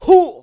xys发力9.wav 0:00.00 0:00.44 xys发力9.wav WAV · 38 KB · 單聲道 (1ch) 下载文件 本站所有音效均采用 CC0 授权 ，可免费用于商业与个人项目，无需署名。
人声采集素材